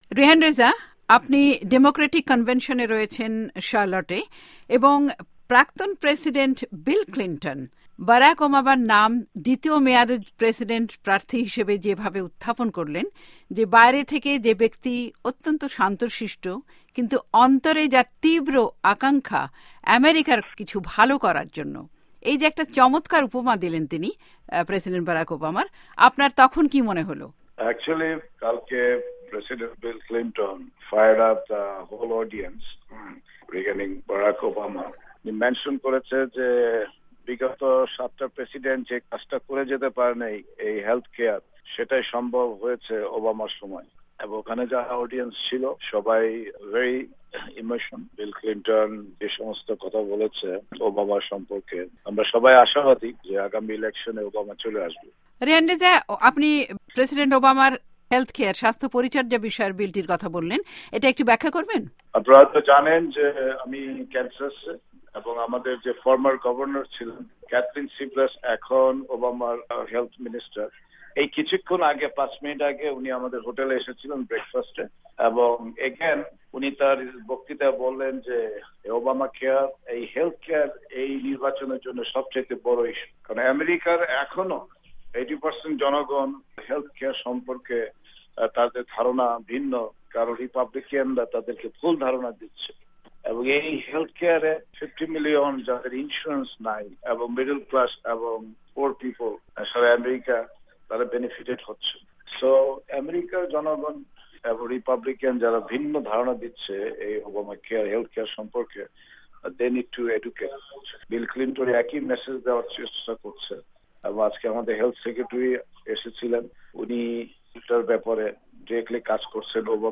আমাদের সঙ্গে এক সাক্ষাতকারে তিনি - বুধবার রাতে প্রাক্তন প্রেসিডেন্ট বিল ক্লিন্টনের ভাষণ, এবারের প্রেসিডেন্ট নির্বাচনে মুসলিম ভোটারদের...